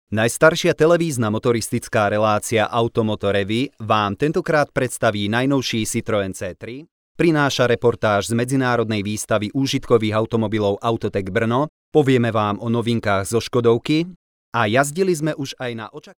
Sprecher slowakisch für Werbung, Industrie, Imagefilme
Kein Dialekt
Sprechprobe: Industrie (Muttersprache):
Professional slovakian voice over talent